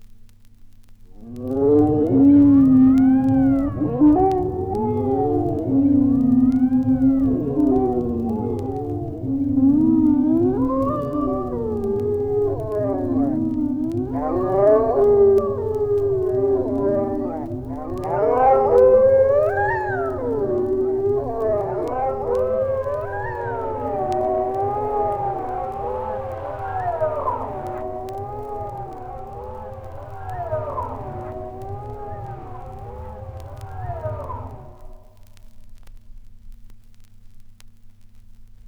• wolves baying at the moon.wav
wolves_baying_at_the_moon_Wjp.wav